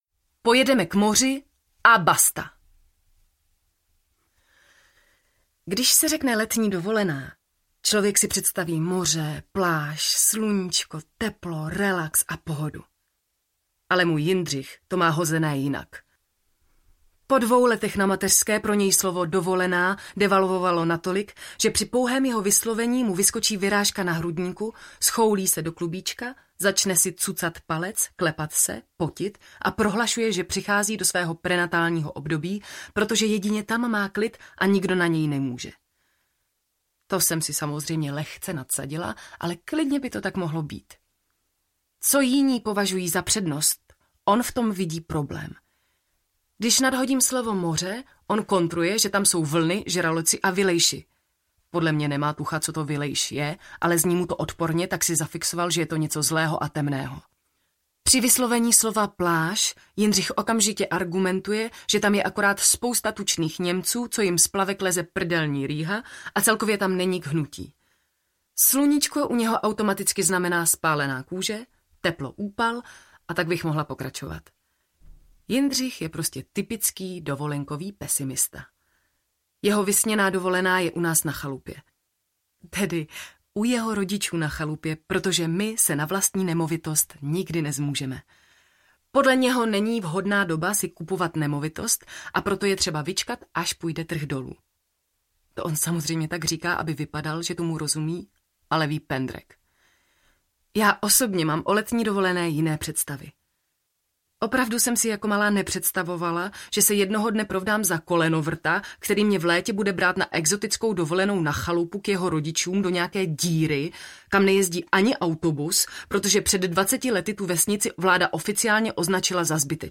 Dovolená s moderním fotrem audiokniha
Ukázka z knihy